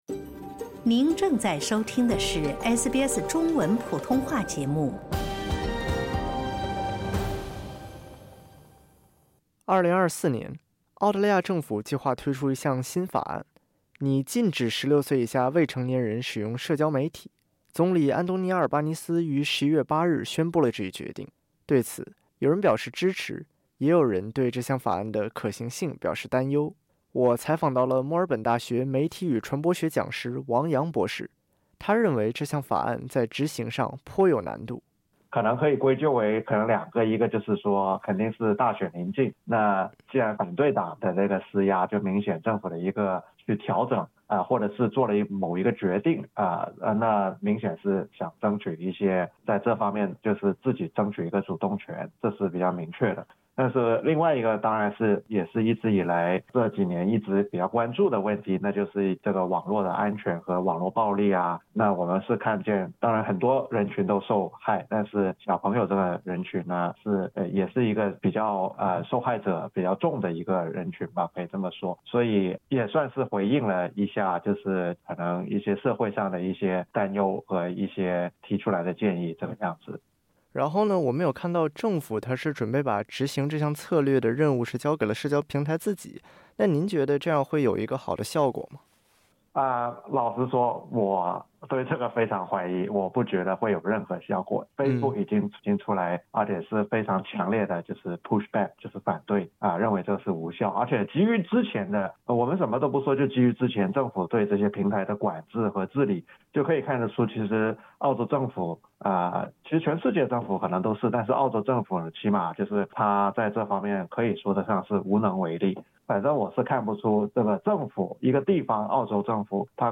总理安东尼·阿尔巴尼斯（Antony Albanese）11月7日表示，政府准备推动立法，禁止16岁以下未成年人接触和使用社交媒体（点击音频，收听详细采访）。